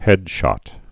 (hĕdshŏt)